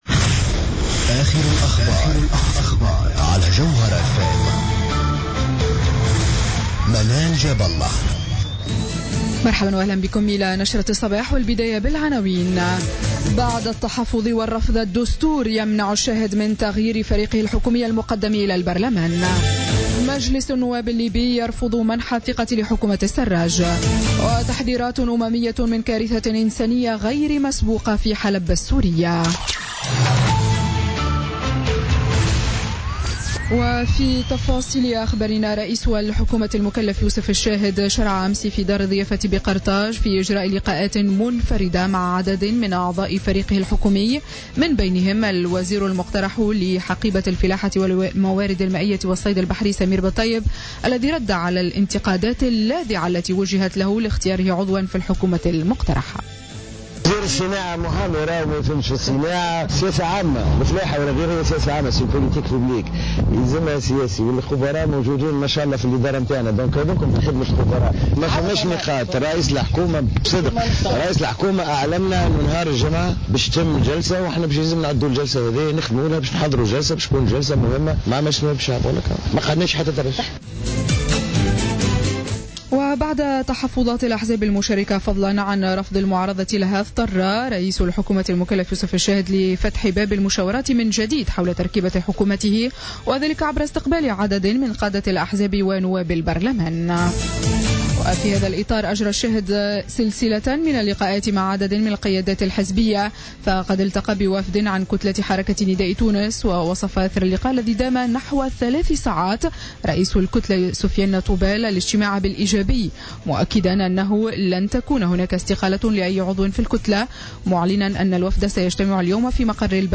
نشرة أخبار السابعة صباحا ليوم الثلاثاء 23 أوت 2016